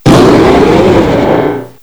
cry_not_mega_latios.aif